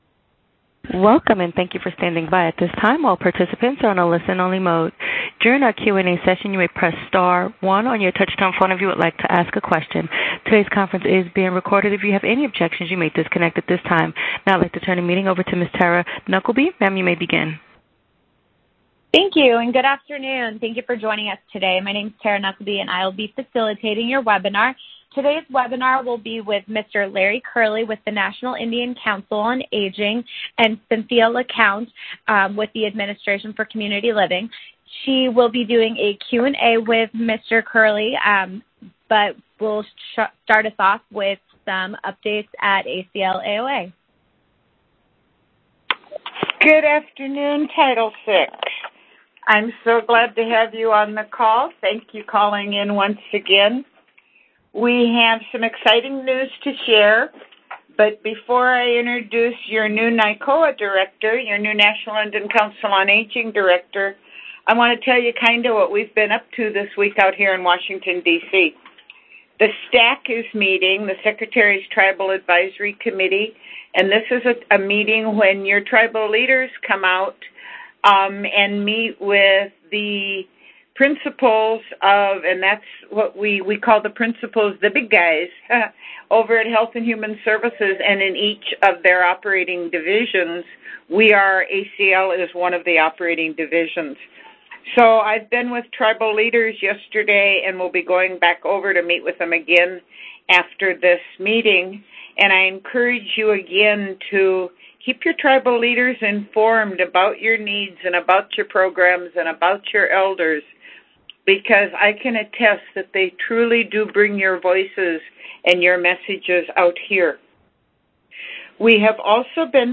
December-Webinar.mp3